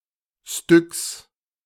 Styx (IPA: [stʏks] oder [ʃtʏks]